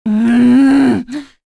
Pavel-Vox_Casting4.wav